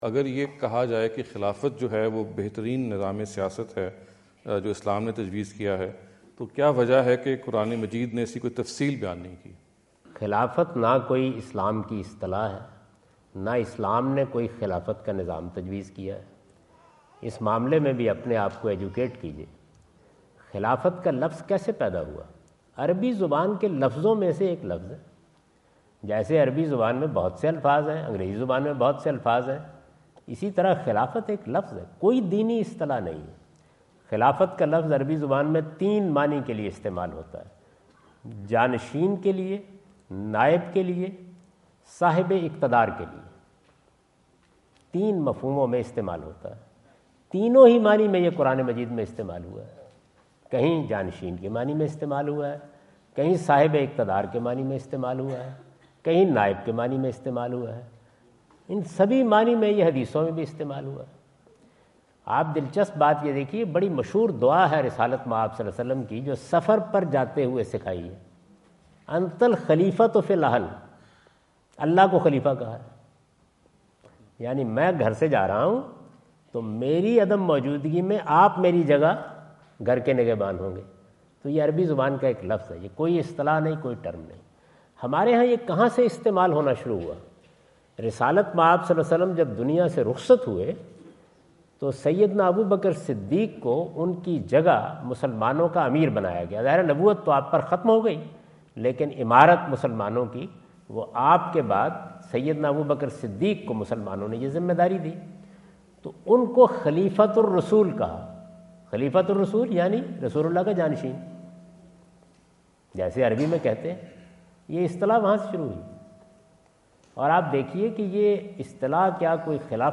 Javed Ahmad Ghamidi answer the question about "ٰIslamic Political System: Caliphate or Democracy" asked at The University of Houston, Houston Texas on November 05,2017.
جاوید احمد غامدی اپنے دورہ امریکہ 2017 کے دوران ہیوسٹن ٹیکساس میں "اسلام کا سیاسی نظام: خلافت یا جمہوریت" سے متعلق ایک سوال کا جواب دے رہے ہیں۔